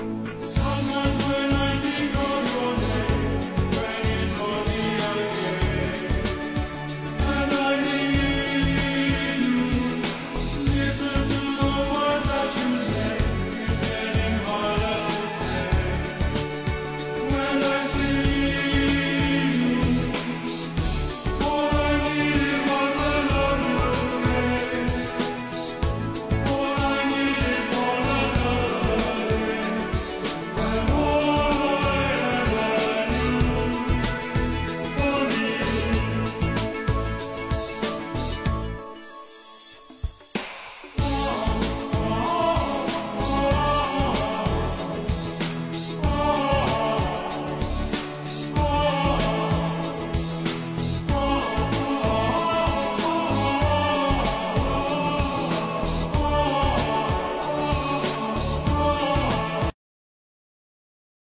Mam tylko urywek zgrany z transmisji może ktoś wie co to za piosenka?